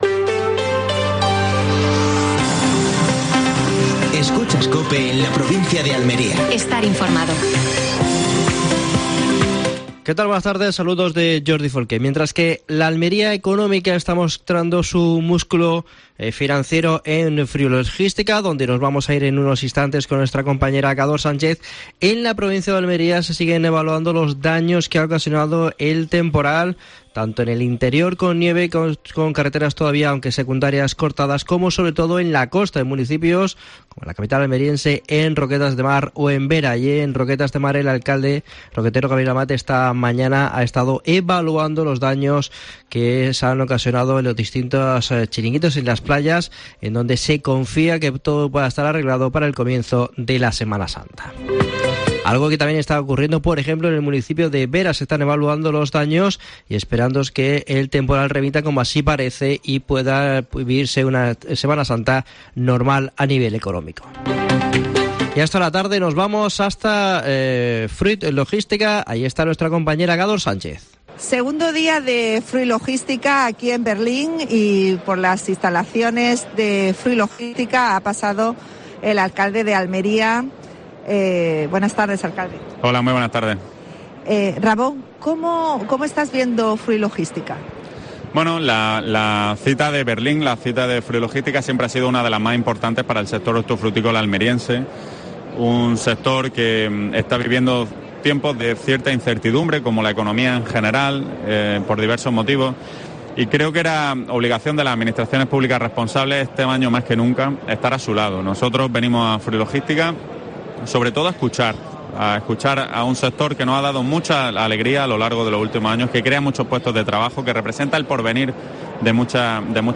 AUDIO: Última hora en Almería. 2ª jornada Fruit Logística (Berlín). Entrevista a Ramón Fernández-Pacheco (alcalde de Almería).